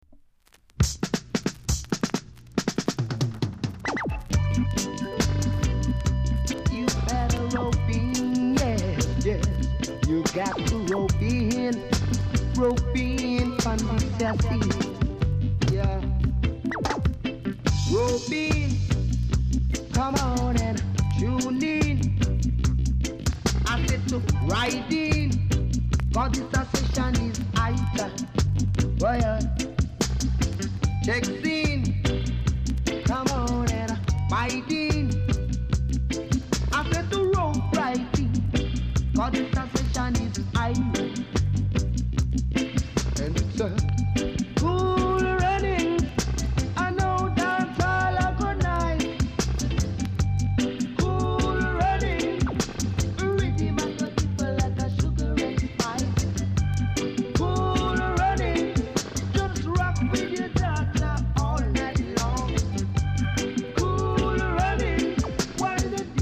※出だしで少しチリチリします。ほかチリ、パチノイズが少しあります。